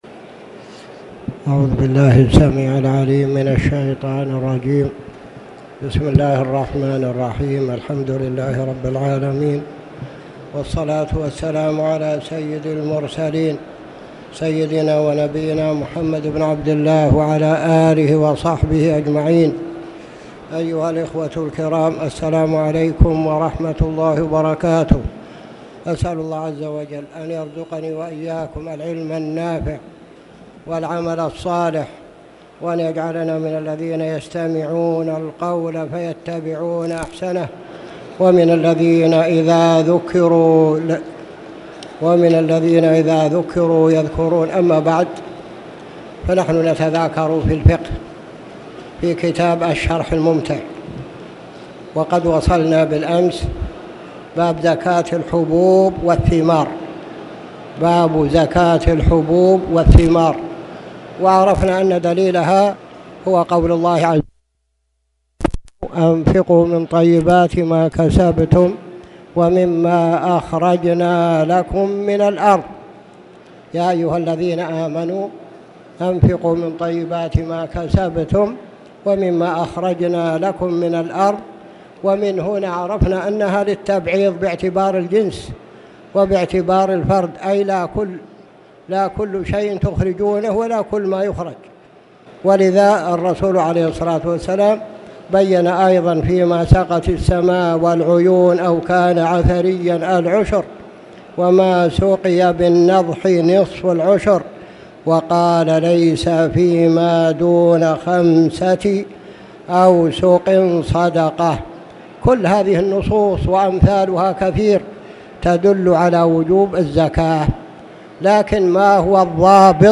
تاريخ النشر ١٤ جمادى الآخرة ١٤٣٨ هـ المكان: المسجد الحرام الشيخ